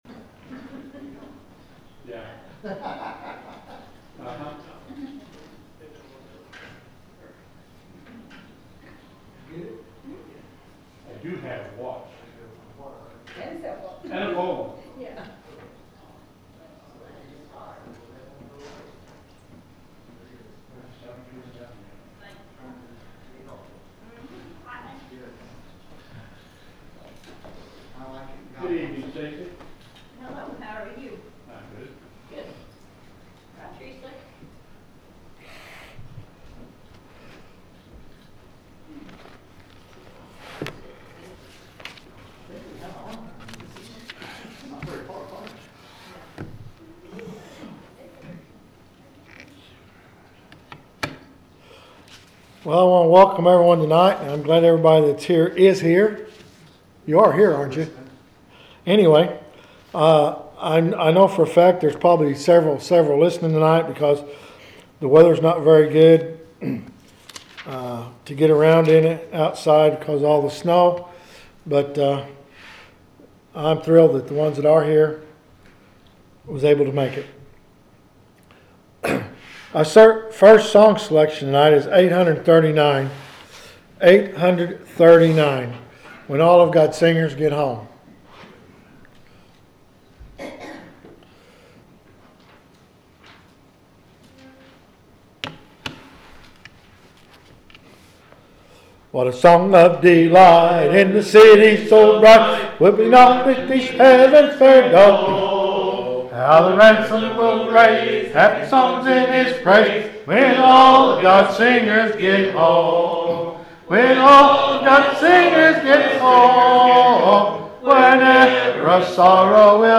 The sermon is from our live stream on 1/28/2026